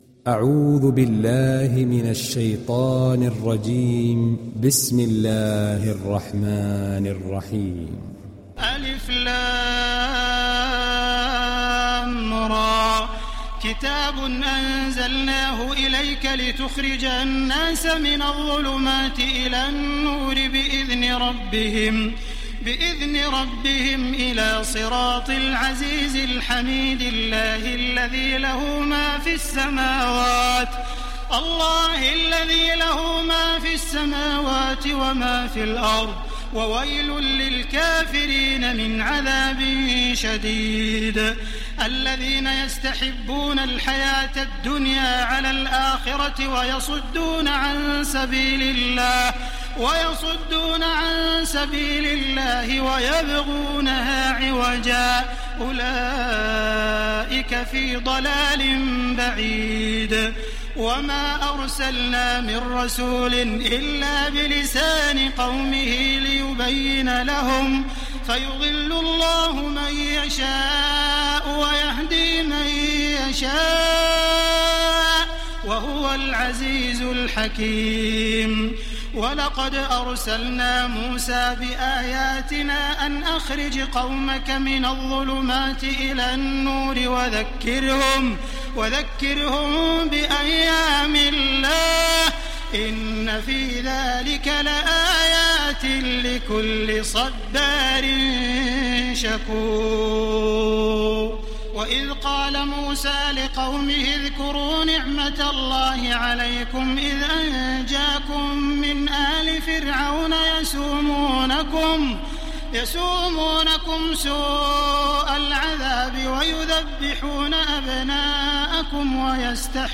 Download Surat Ibrahim Taraweeh Makkah 1430